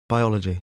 2. biology (n.) /bai’ɔlədʤi/ sinh vật học